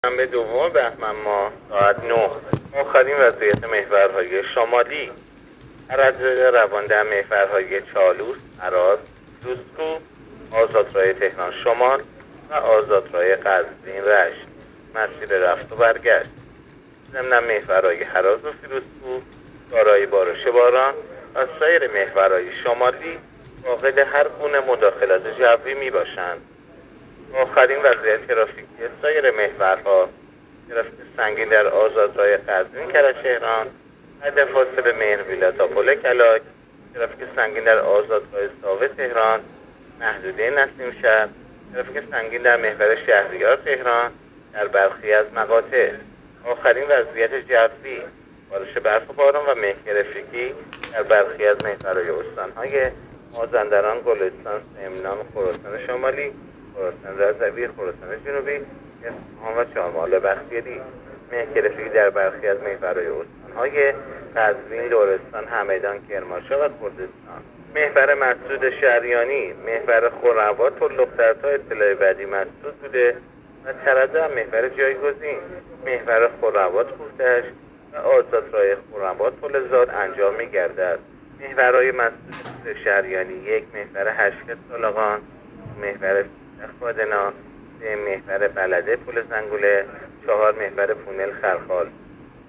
گزارش رادیو اینترنتی از آخرین وضعیت ترافیکی جاده‌ها ساعت ۹ دوم بهمن؛